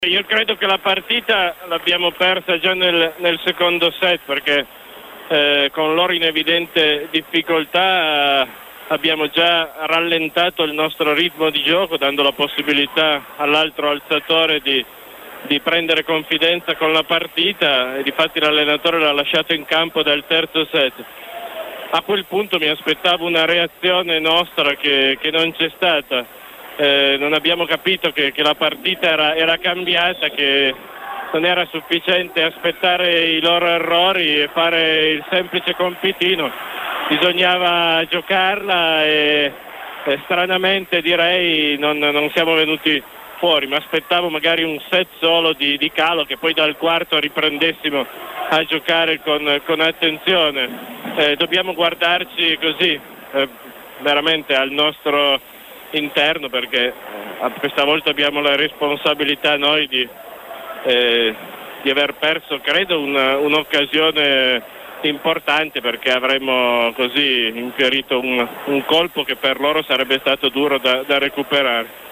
Interviste mp3